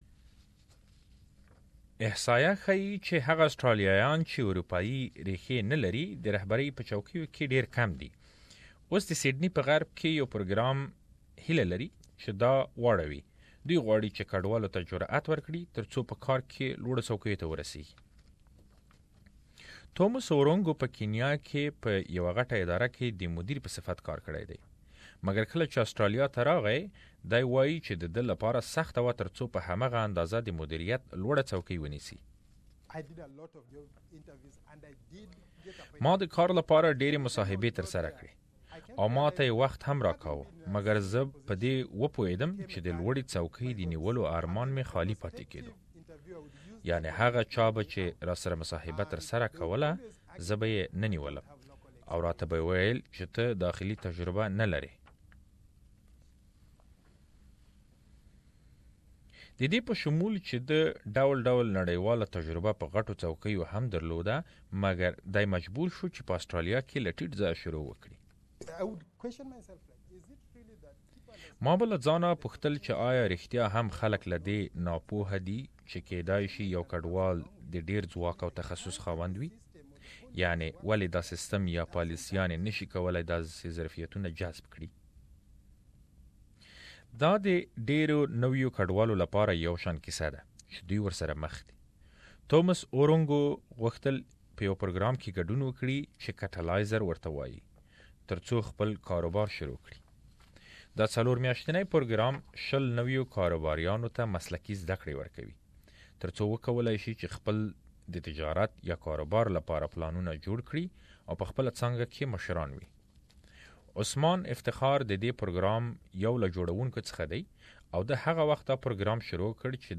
The low representation of people from non-European background in leadership positions means Australia is missing out. For more details please listen to this report.